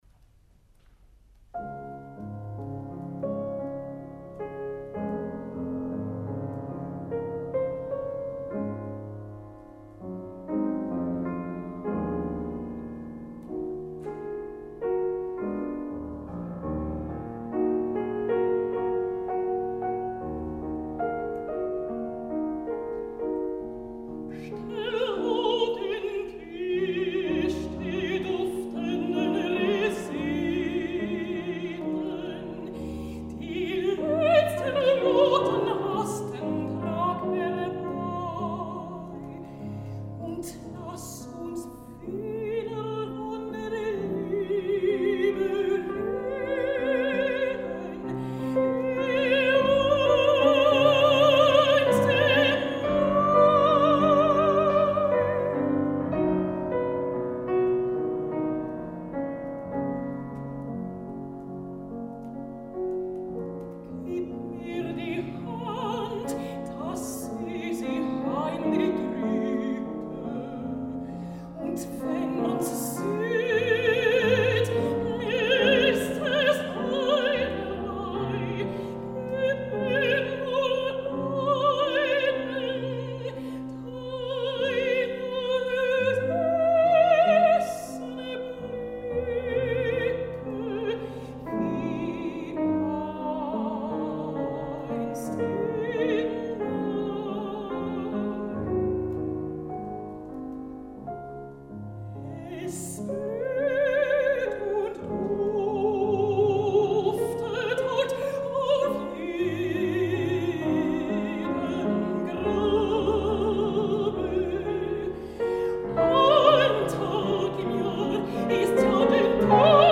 mezzosoprano
piano
Com podreu comprovar un cop més, a la preciosa i càlida veu de la Koch, cal sumar-li la sensibilitat idònia per cantar aquest repertori i l’afinitat amb el músic austríac, cosa que ens fa esperar amb més ganes, si cal, aquest retorn liceista.